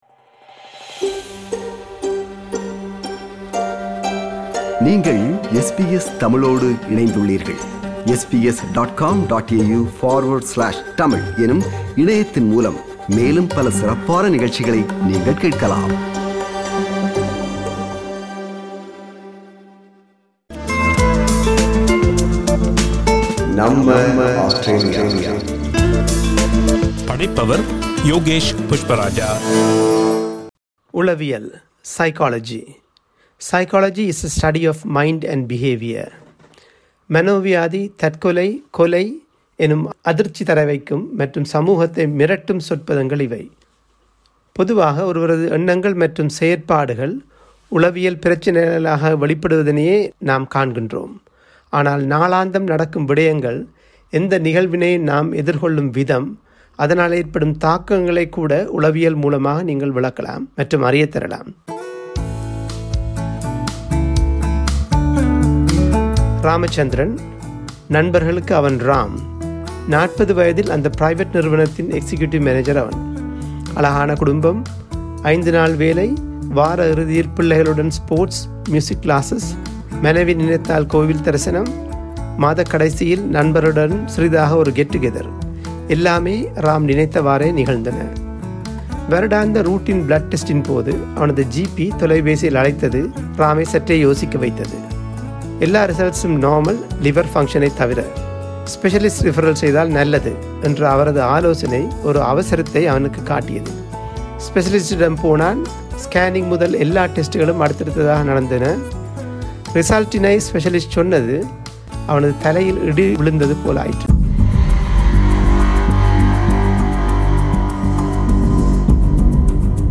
a Consultant Psychiatrist, explains grief and loss reactions in Tamil.